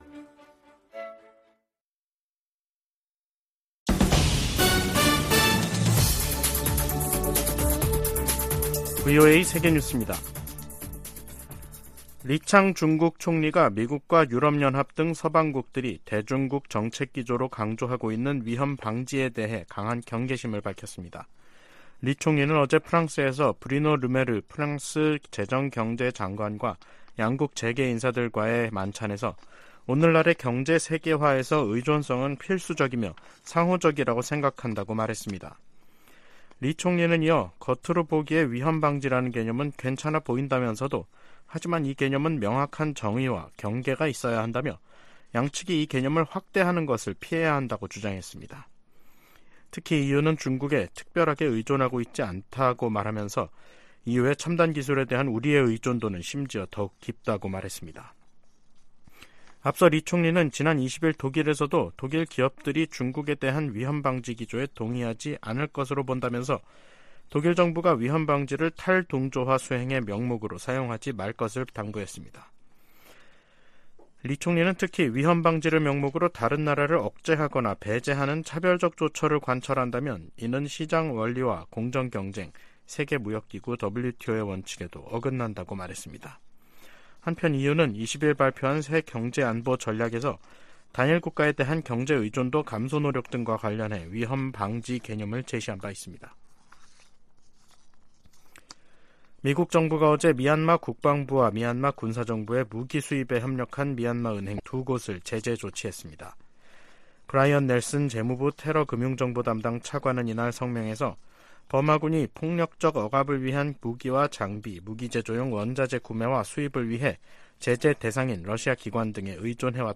VOA 한국어 간판 뉴스 프로그램 '뉴스 투데이', 2023년 6월 22일 2부 방송입니다. 미 국무부는 중국이 북한 문제를 해결할 역량과 책임이 있다는 점을 거듭 강조했습니다. 미국은 자산 동결 등 조치를 통해 북한이 탈취 암호화폐를 미사일 프로그램에 사용하는 것을 막았다고 법무부 고위 관리가 밝혔습니다. 주한미군 고고도 미사일 방어체계 즉 사드(THAAD) 기지가 인체에 미치는 영향은 미미하다고 한국 정부의 환경영향 평가가 결론 지었습니다.